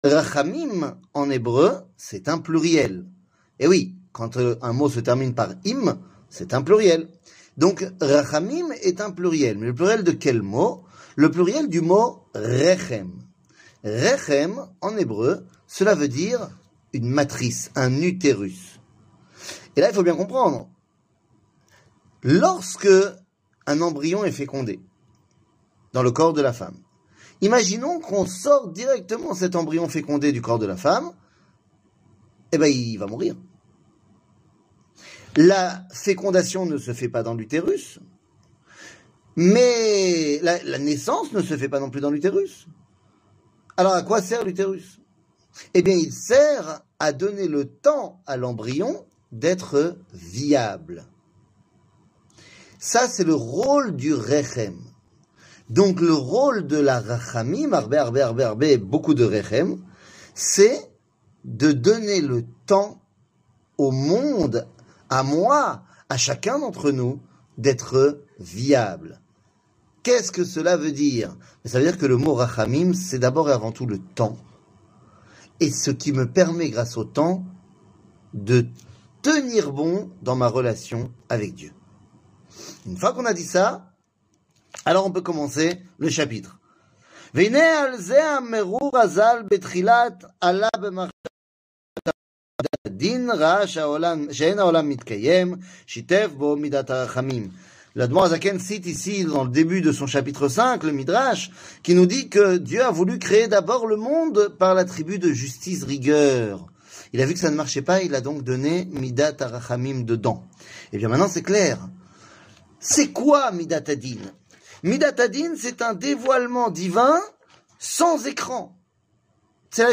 שיעור מ 19 יולי 2023
שיעורים קצרים